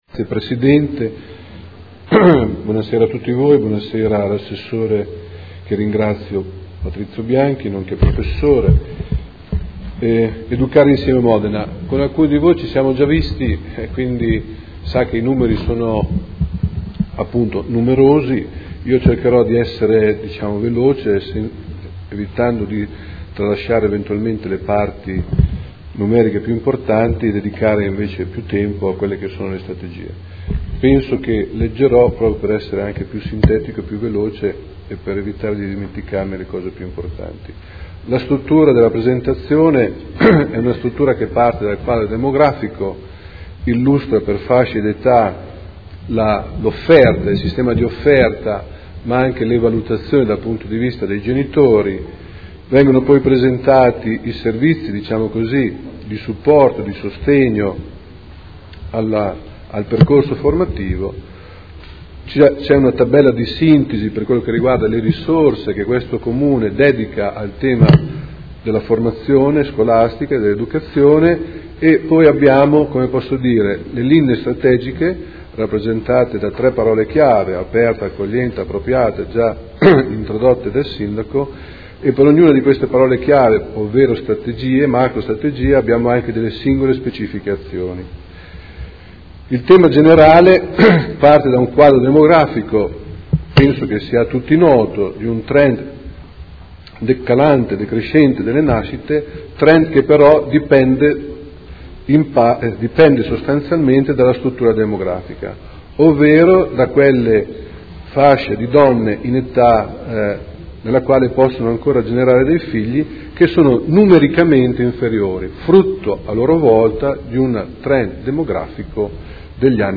Seduta del 17/09/2015. Intervento sul progetto "Educare Insieme", piano della buona scuola a Modena